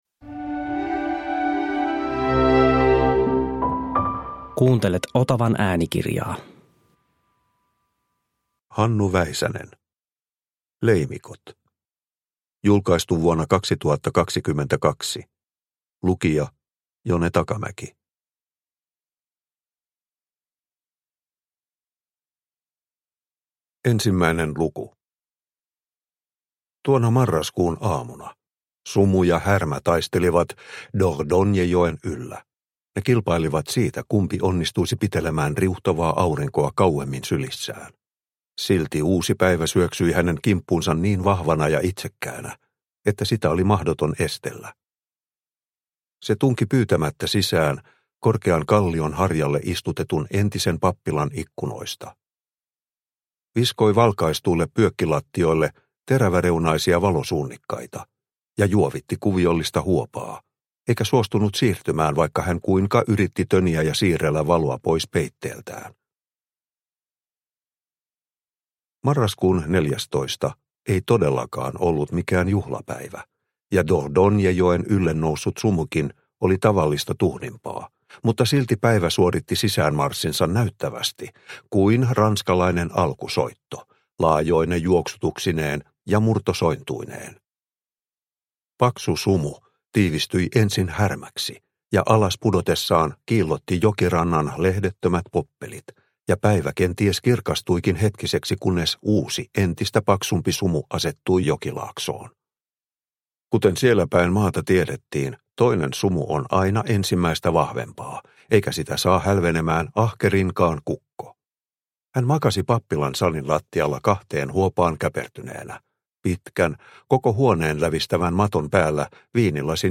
Leimikot – Ljudbok – Laddas ner